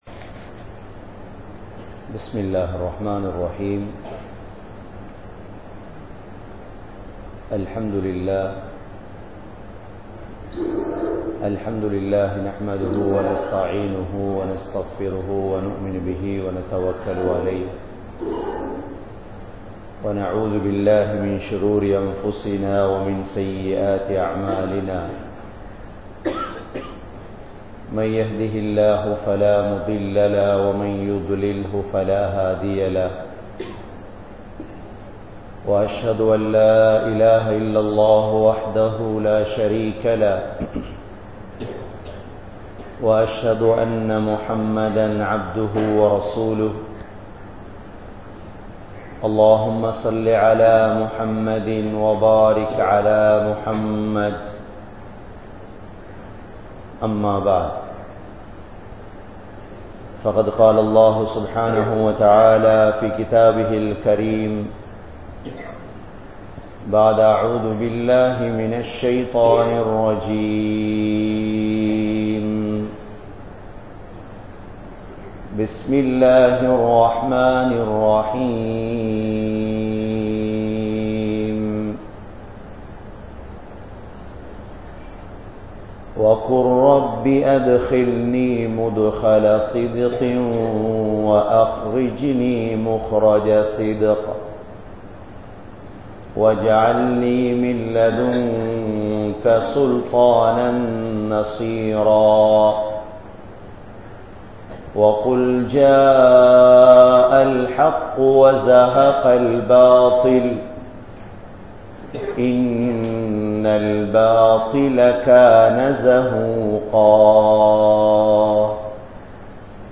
Poiyai Tholilaaha Seivoar (பொய்யை தொழிலாக செய்வோர்) | Audio Bayans | All Ceylon Muslim Youth Community | Addalaichenai